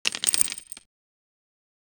A crisp, wooden clunk of stacking resources like apples or ammo boxes in a medieval idle game. Short, satisfying sound, lasting 1-2 seconds, fitting a cartoonish 2D isometric world’s resource management 0:02 Created Jul 4, 2025 8:58 AM
a-crisp-wooden-clunk-of-42pxj5x6.wav